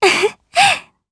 Laudia-Vox_Happy1_jp.wav